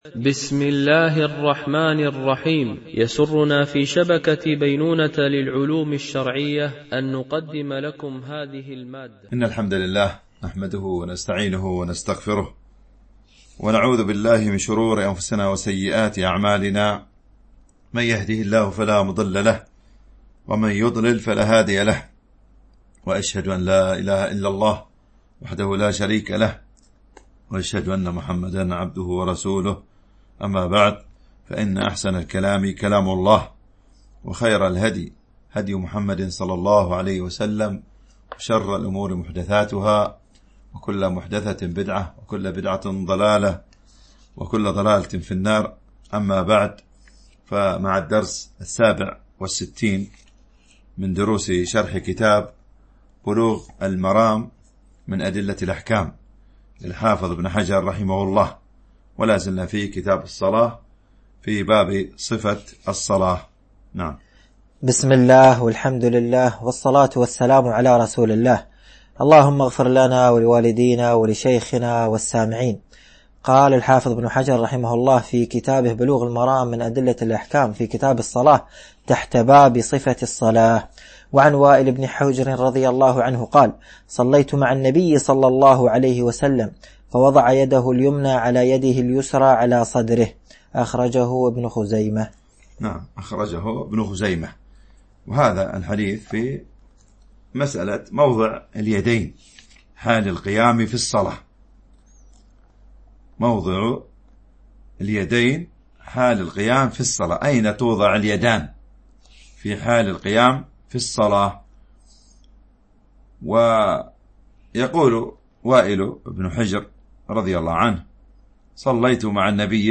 التنسيق: MP3 Mono 22kHz 32Kbps (CBR)